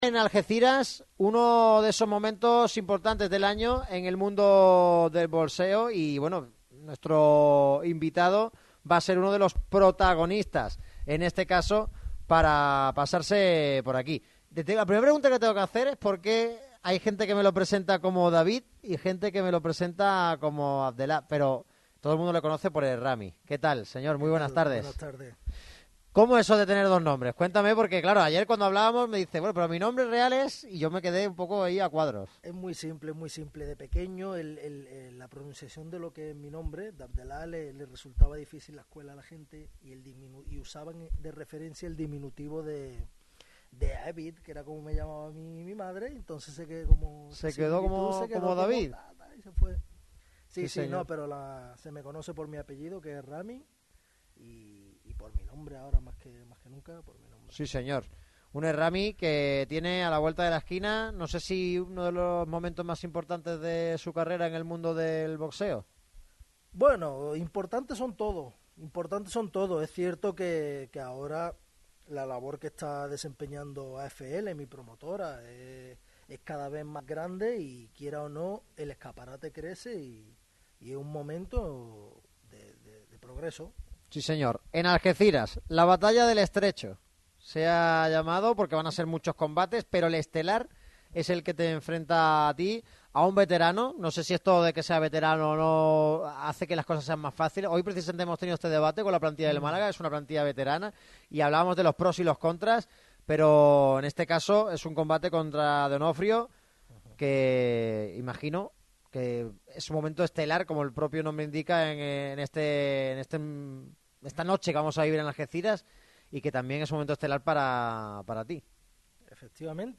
El boxeador atendió a Radio MARCA Málaga en un programa celebrado en Footfay Center, donde se entrena y se recupera a menudo.